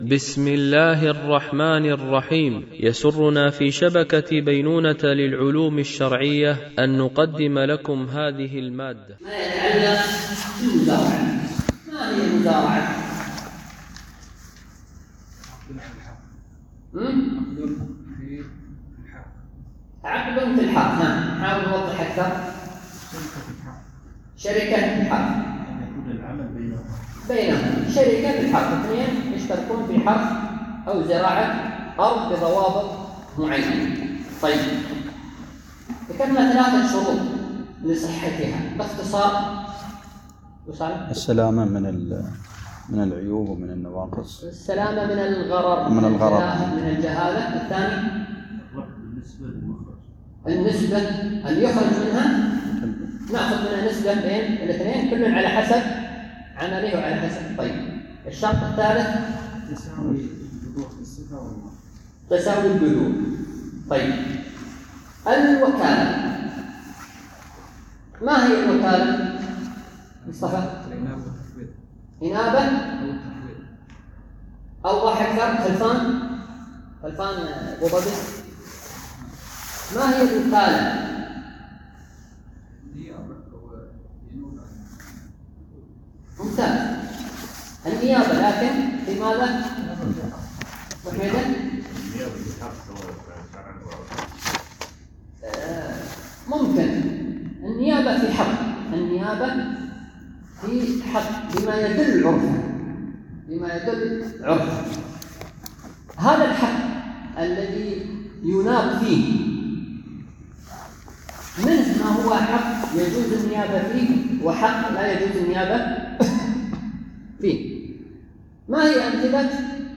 التنسيق: MP3 Mono 44kHz 96Kbps (VBR)